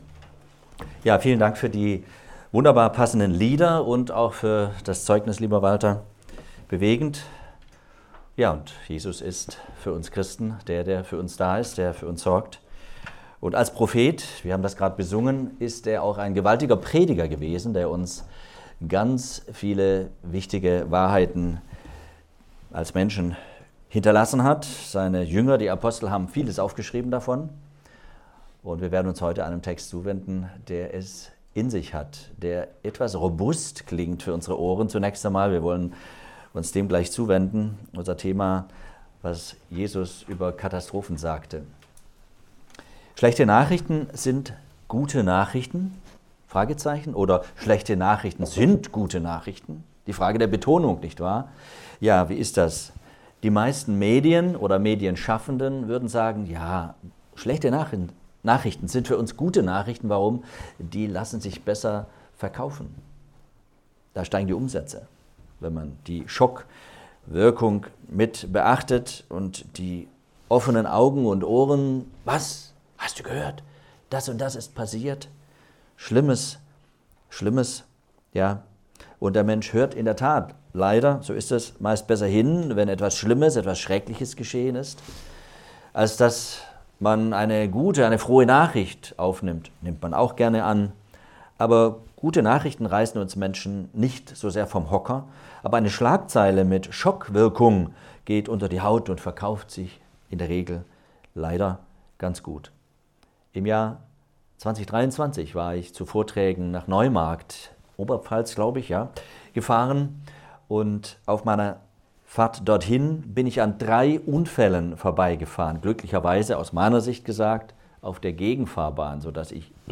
Ostersonntag-Gottesdienst am 12.04.2020